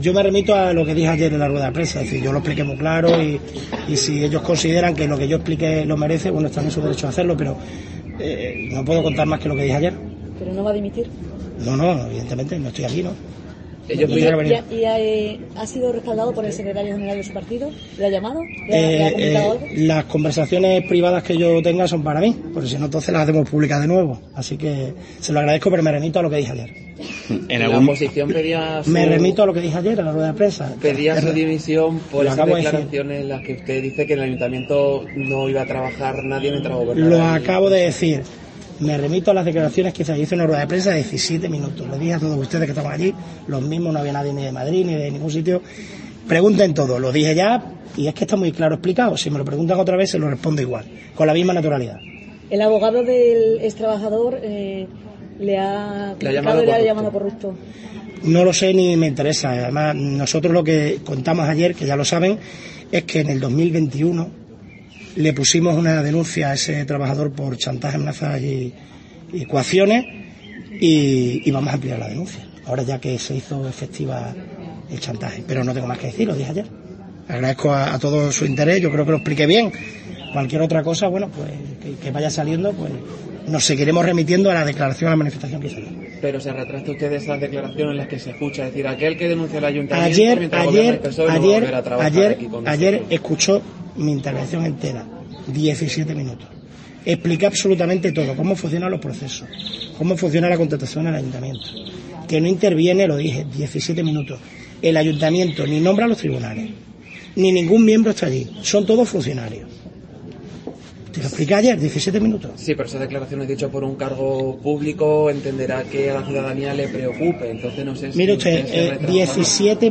"No voy a dimitir, estoy aquí en el acto, me voy ahora a la presentación también en el aeródromo y por tanto no tengo más que decir", ha espetado el primer edil a preguntas de los medios antes de su asistencia este viernes a primera hora en a un desayuno con los usuarios del Centro Padre Cristóbal de Mérida.